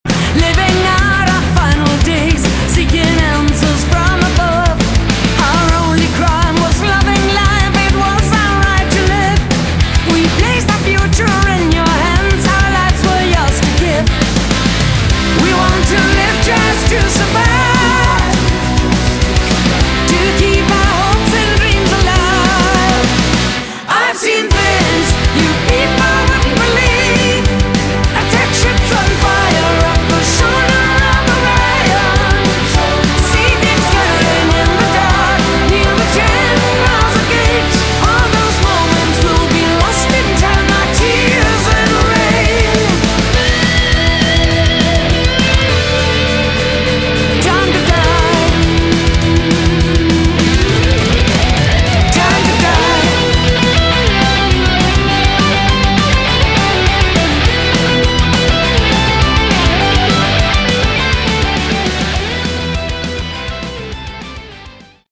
Category: Hard Rock
Vocals, Backing Vocals
Guitar, Bass, Keyboards
Drums, Percussion